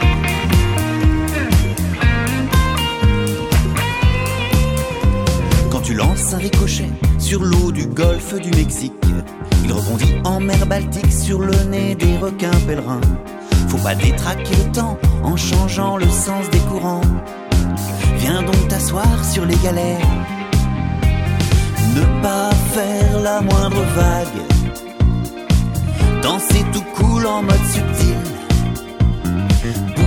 un duo de musiciens-chanteurs pour le jeune public